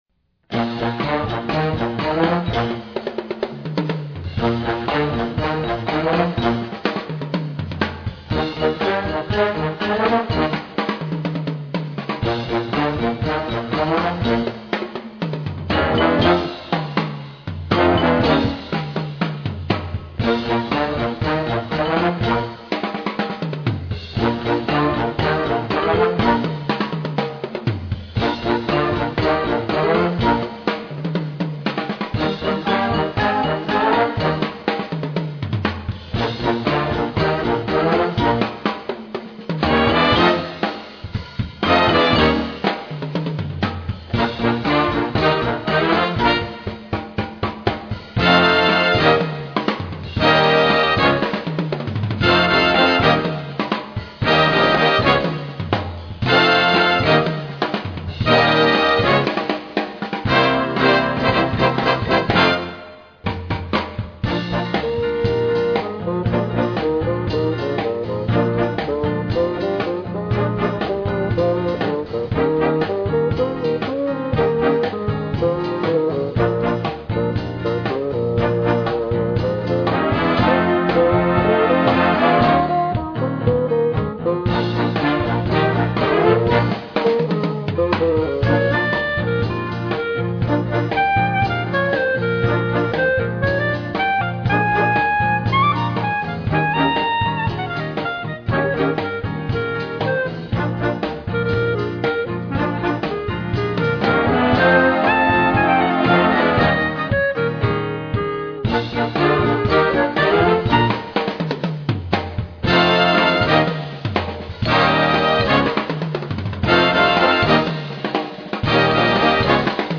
Gattung: for Wind Band and Jazz ensemble
Besetzung: Blasorchester